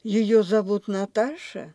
Предударная часть произносится на среднем тоне. Ударная часть начинается с более высокой точки по сравнению с предударной. Далее в пределах слога тон продолжает повышаться. Эта особенность ИК-3 представляет большую трудность для нерусских. На заударной части происходит понижение тона ниже среднего. Нельзя допускать повышение тона в заударной части.